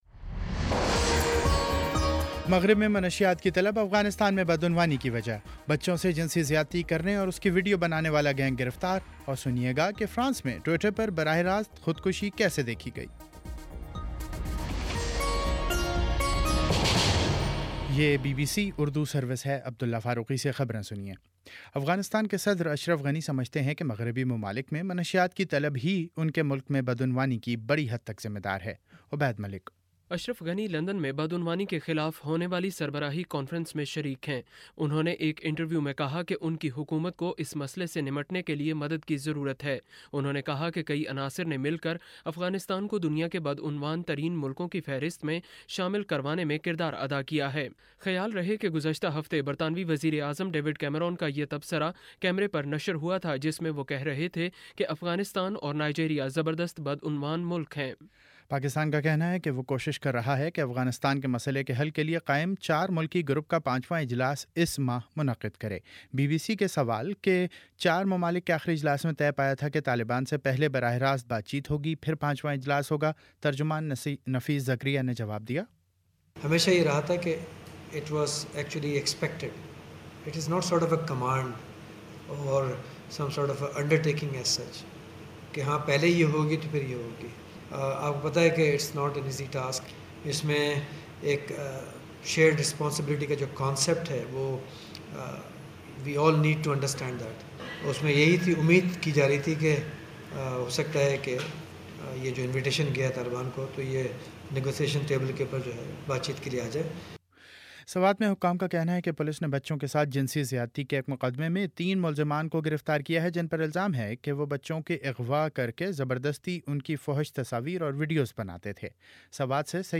مئی 12: شام سات بجے کا نیوز بُلیٹن